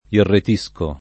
vai all'elenco alfabetico delle voci ingrandisci il carattere 100% rimpicciolisci il carattere stampa invia tramite posta elettronica codividi su Facebook irretire v.; irretisco [ irret &S ko ], ‑sci — in Dante, inretire